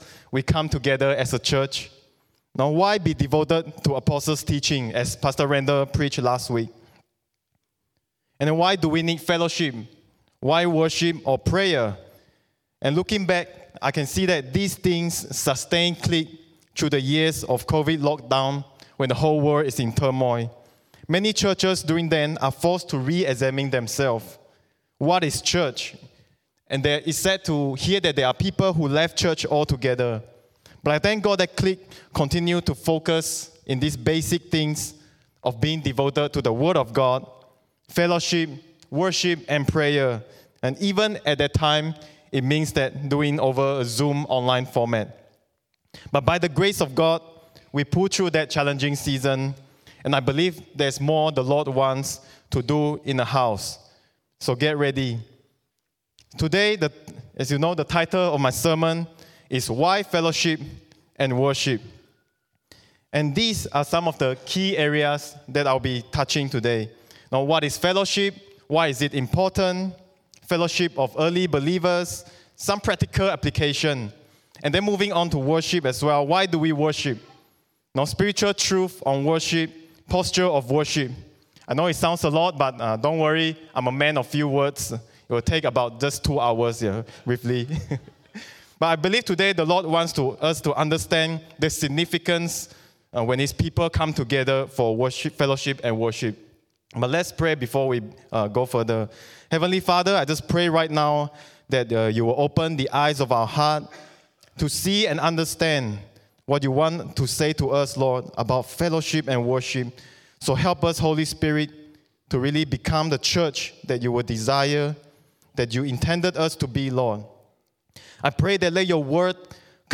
English Worship Service - 15th January 2022
Sermon Notes